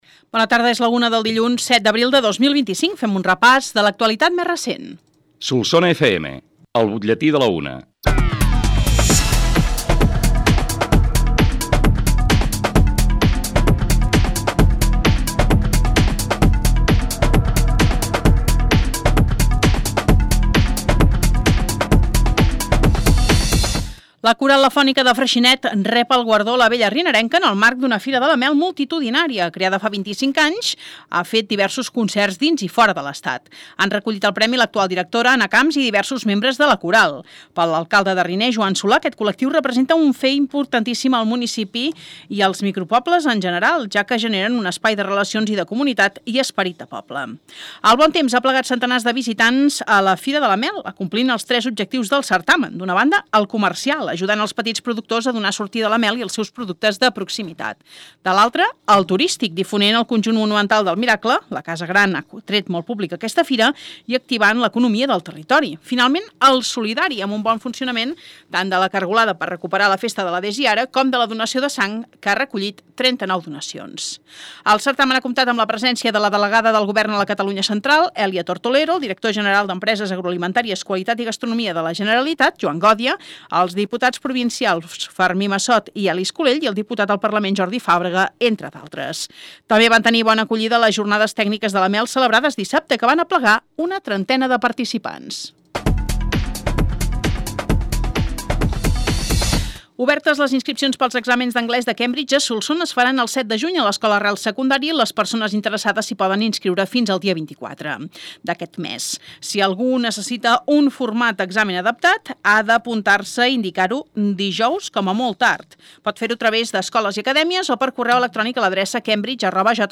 L’ÚLTIM BUTLLETÍ